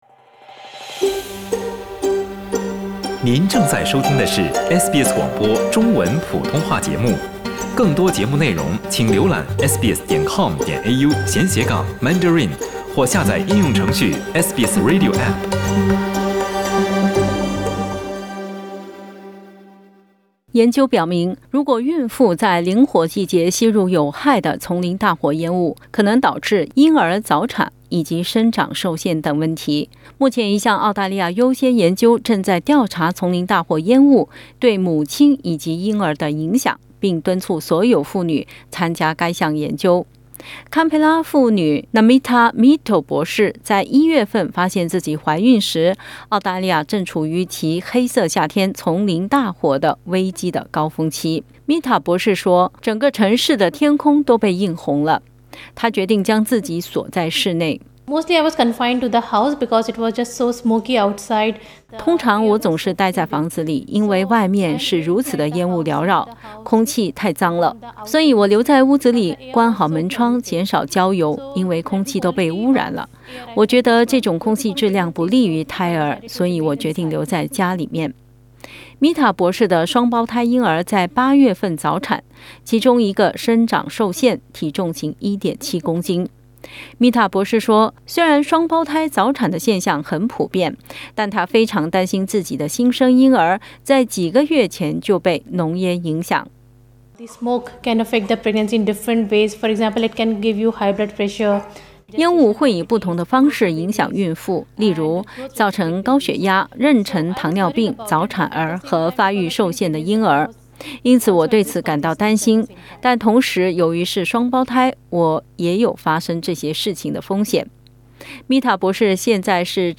（请听报道） 澳大利亚人必须与他人保持至少 1.5 米的社交距离，请查看您所在州或领地的最新社交限制措施。